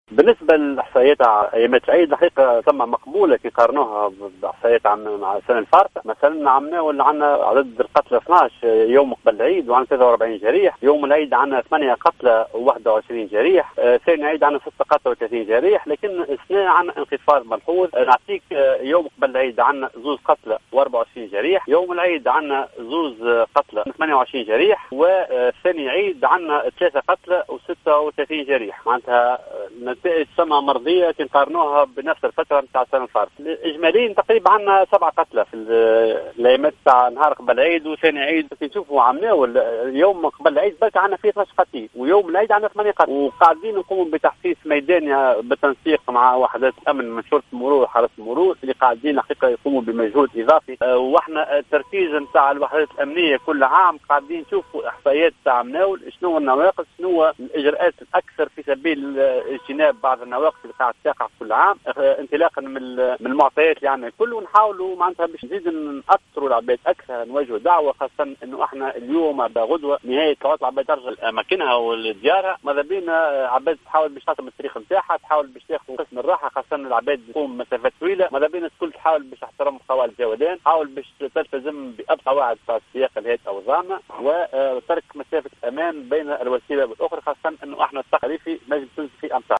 أعلن مدير المرصد الوطني للمرور العقيد فيصل الخميري، في اتصال هاتفي لجوهرة اف ام اليوم السبت، عن وفاة سبعة اشخاص وجرح اكثر من ثمانين آخرين اثر حوادث مرور جدت ايام عيد الاضحى وهي نسبة تعتبر منخفضة مقارنة بالسنة الماضية .